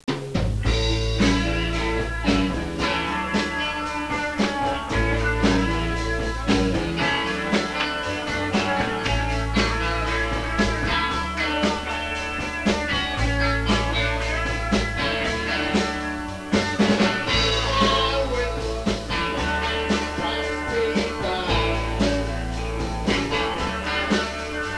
99.６月ミントンハウスでの演奏曲目